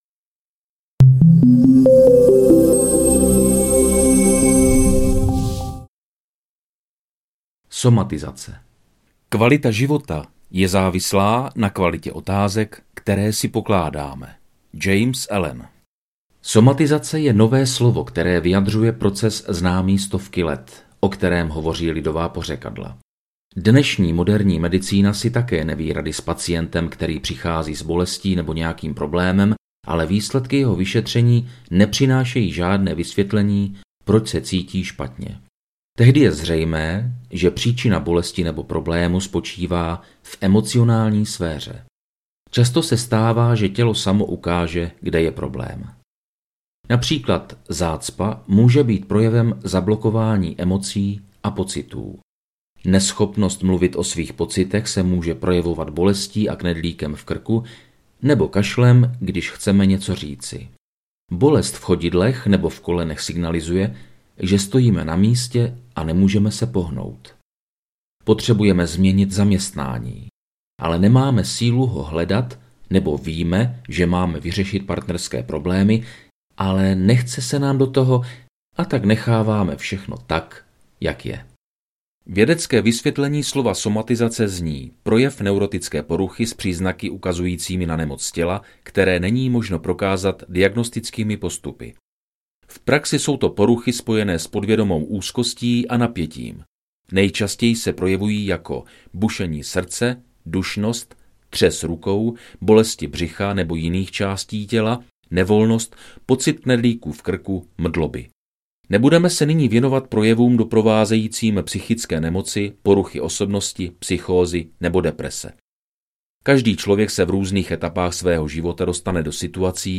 Zdravá mysl audiokniha
Ukázka z knihy